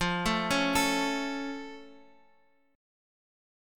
F+ chord